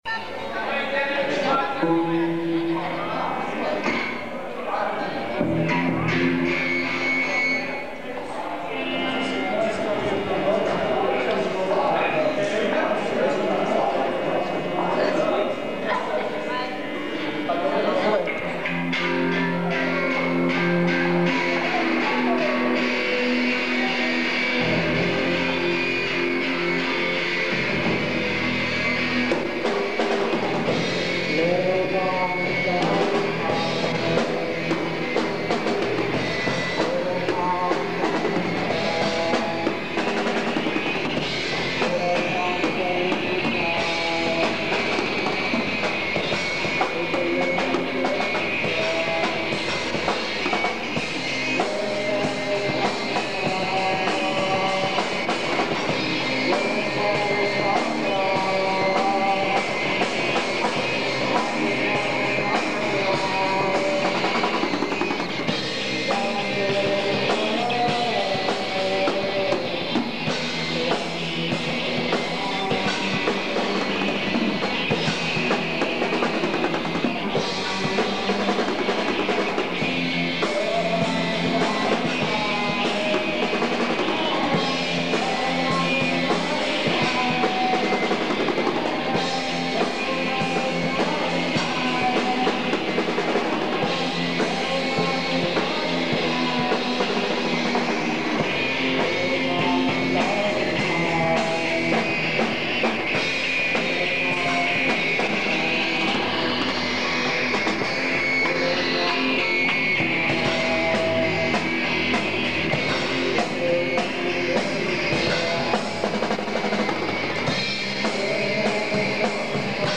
КОНЦЕРТ В КИНОТЕАТРЕ "ПИОНЕР"
электрическая гитара
ударные.